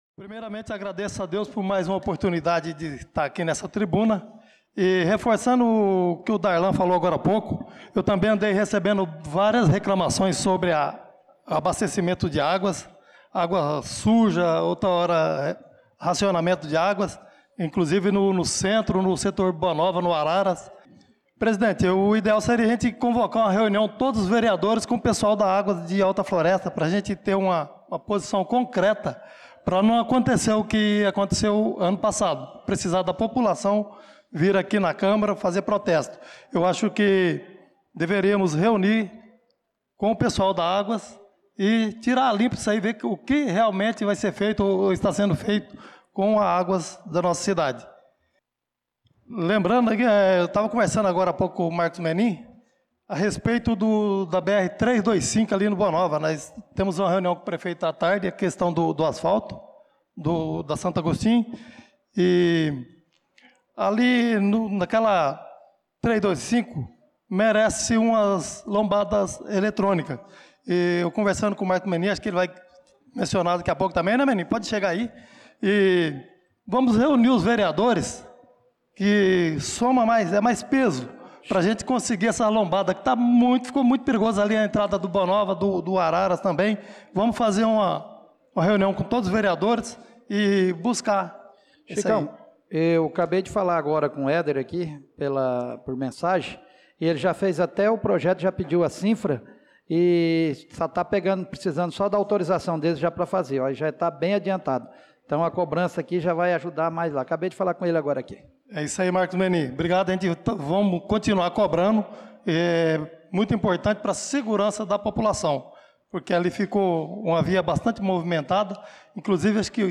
Pronunciamento do vereador Chicão Motocross na Sessão Ordinária do dia 18/08/2025.